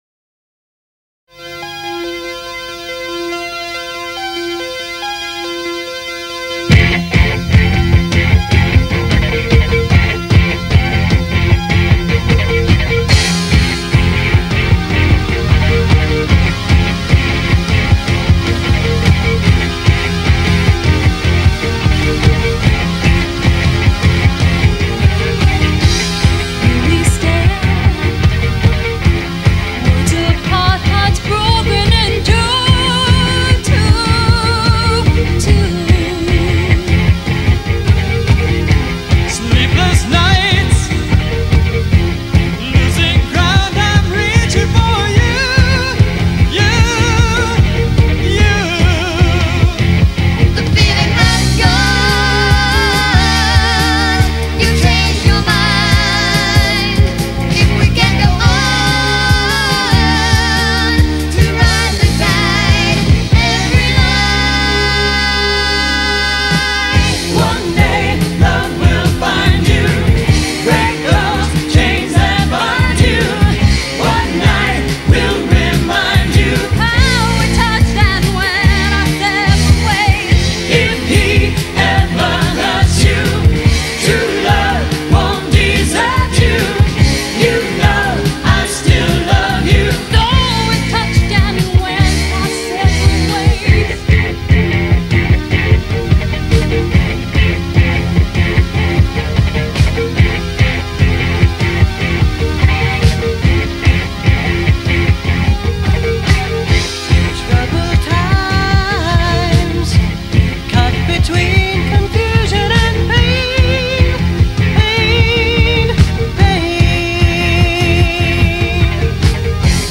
кавер версия
Концерт есть, а вот плей листа к нему нет.